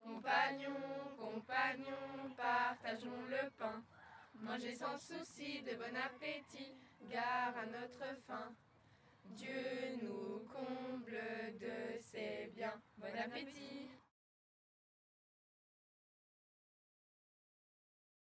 Type : chant de mouvement de jeunesse
Interprète(s) : Patro de Pontaury